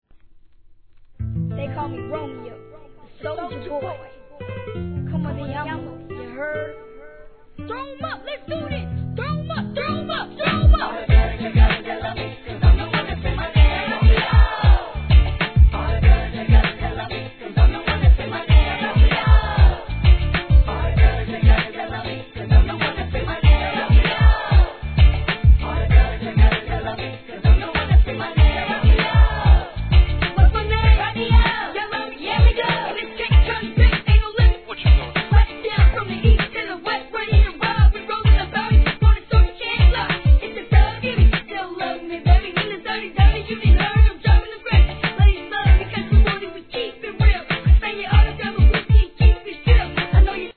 HIP HOP/R&B
ラテン調のノリのいいトラックにフックのコーラスがテンション上がります！